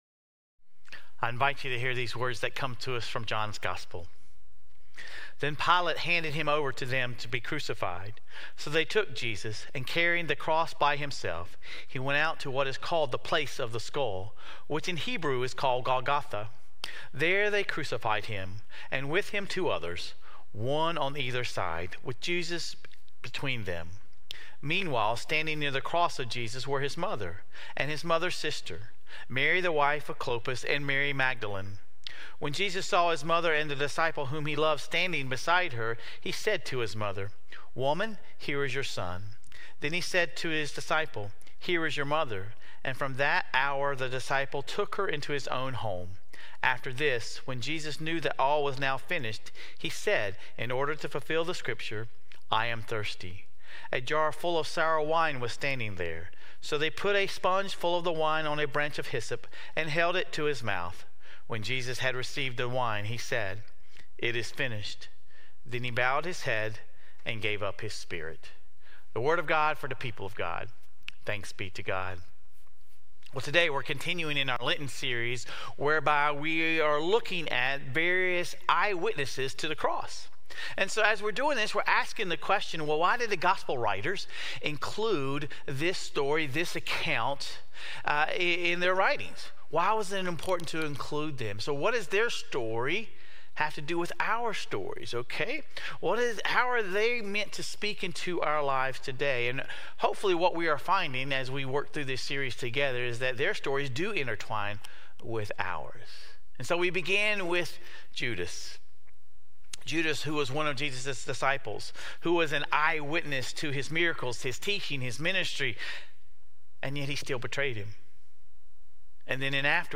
Sermon Reflections: Imagine yourself as Mary standing at the foot of the cross.